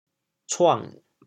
How to say the words 闯 in Teochew？
TeoChew Phonetic TeoThew cuêng3